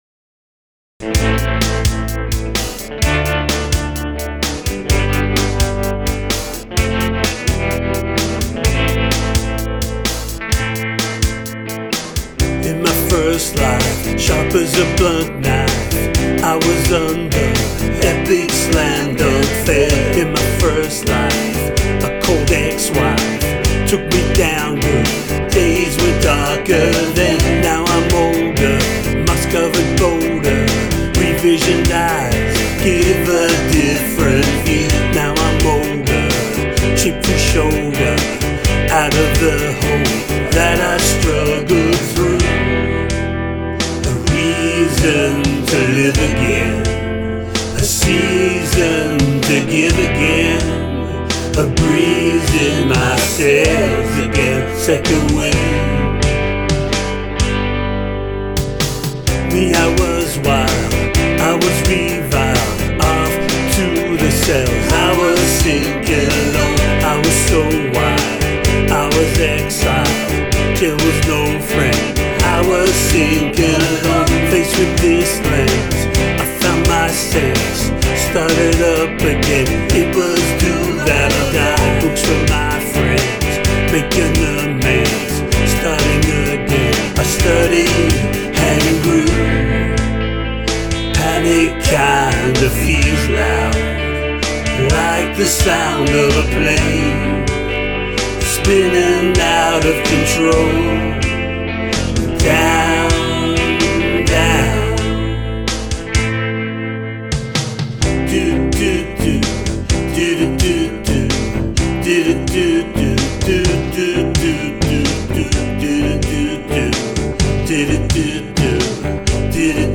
I found myself humming it the other day, it’s catchy and I love the tremelo guitar effect. And I really like the backing voices double tracked onto fourth line of each verse.